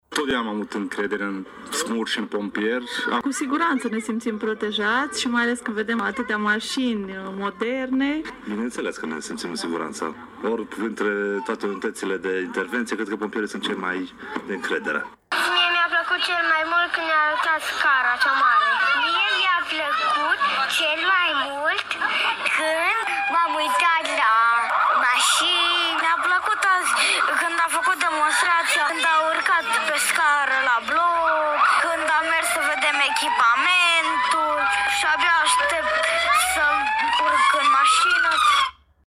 Pompierii mureşeni au marcat astăzi Ziua Informării Preventive, iniţiativă ce are loc la nivel european în fiecare zi de marţi, 13.
Adulţii prezenţi la eveniment au spus că au mare încredere în pompieri, iar copii s-au bucurat de tehnica prezentată: